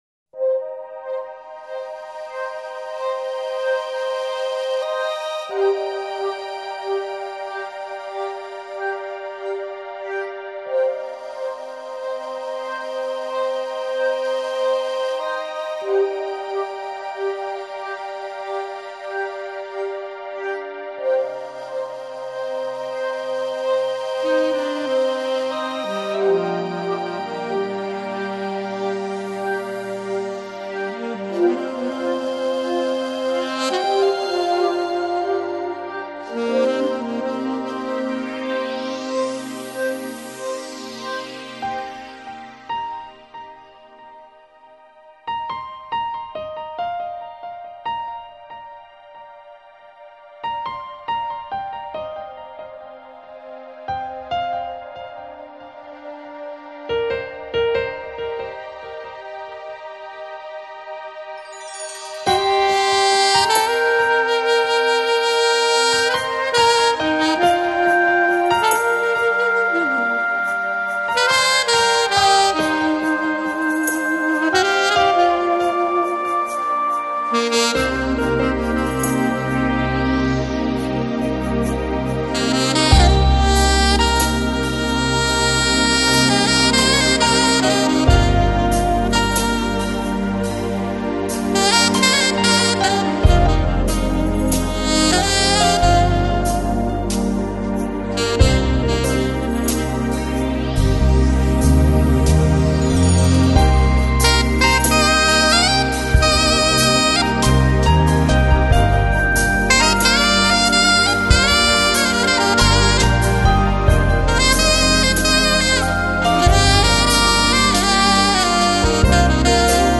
Жанр: Electronic, Lounge, Chill Out, Downtempo Год издания